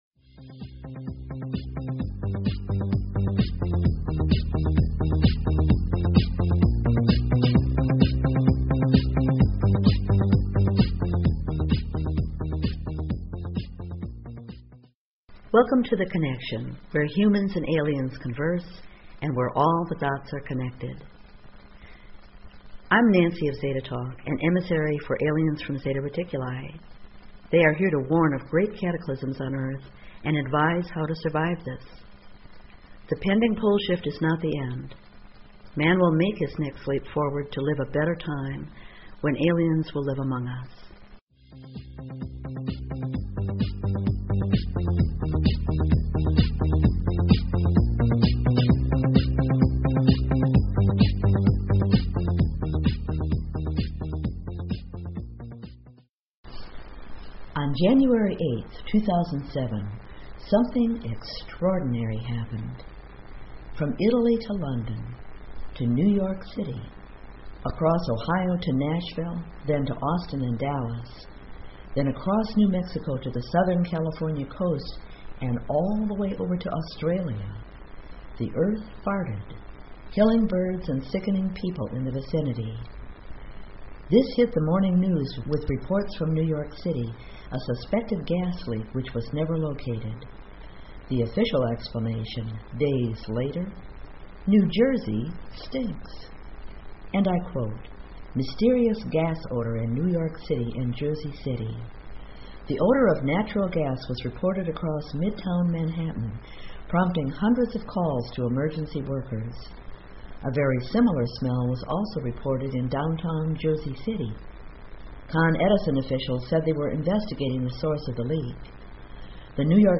Talk Show Episode, Audio Podcast, The_Connection and Courtesy of BBS Radio on , show guests , about , categorized as